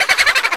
Voice clip from Luigi's Mansion
Ghost-005.oga.mp3